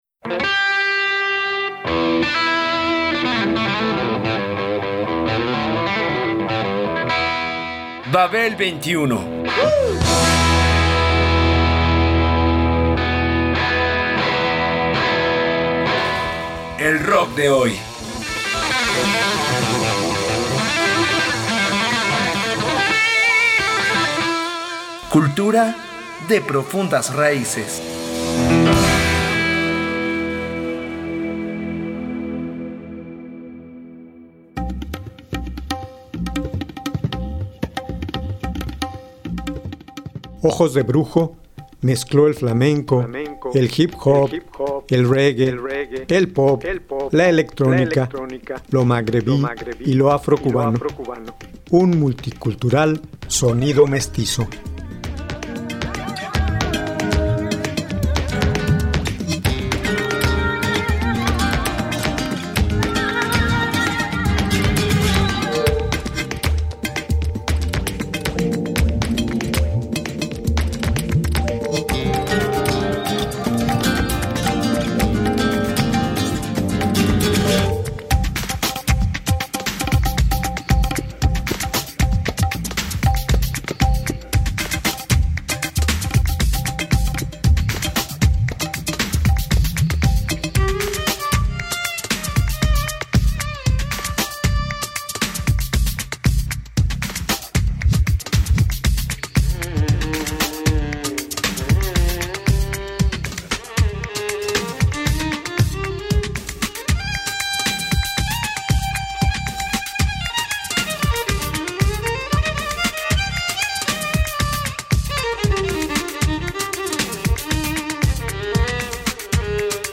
Con su mezcla de flamenco, hip hop, reggae, pop, música electrónica, dub, sonidos magrebíes y afrocubanos.